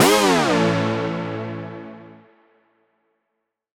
Index of /musicradar/future-rave-samples/Poly Chord Hits/Ramp Down